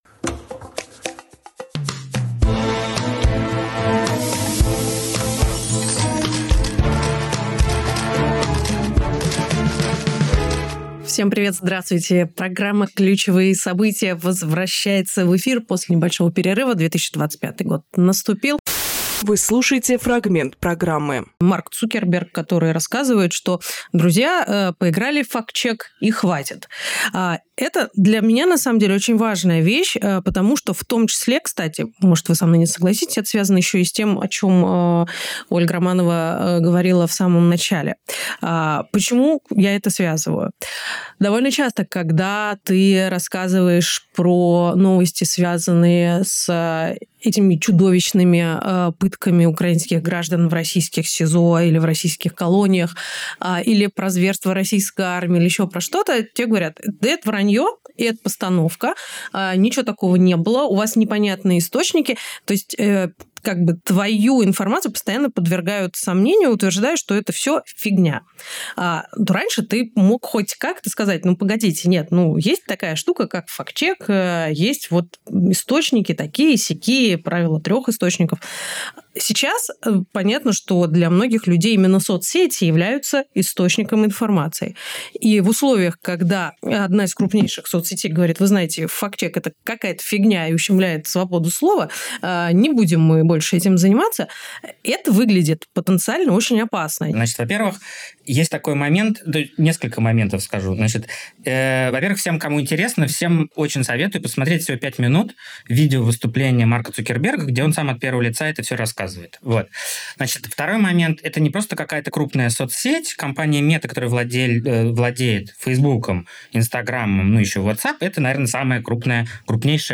Фрагмент эфира от 10.01.25